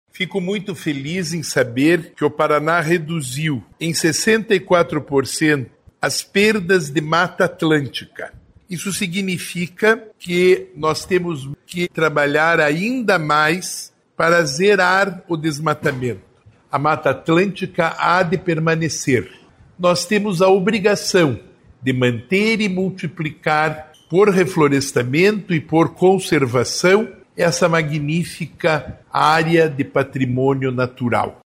O secretário de Estado do Desenvolvimento Sustentável, Rafael Greca, disse que o resultado é positivo, mas que é necessário trabalhar para zerar o desmatamento”.